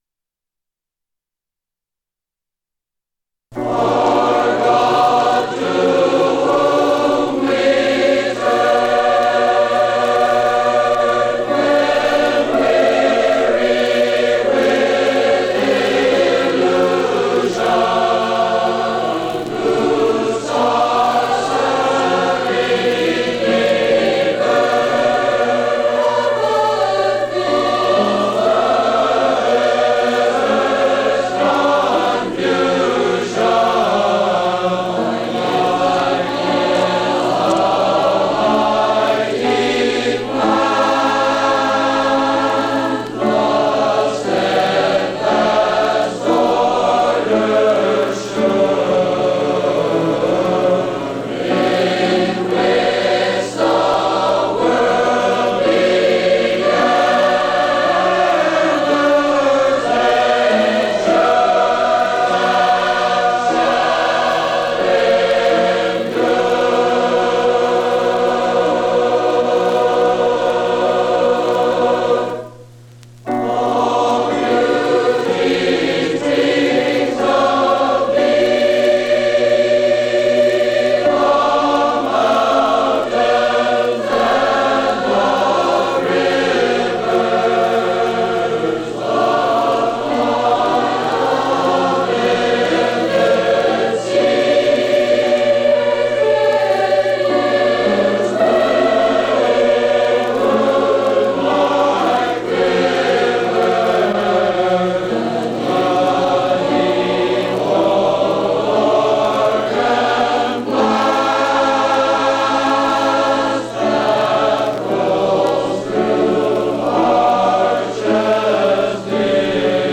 3de28a2faa31e871435b8f88850c7a5a1efc9cbd.mp3 Title 1954 Music in May chorus performance recording Description An audio recording of the 1954 Music in May chorus performance at Pacific University.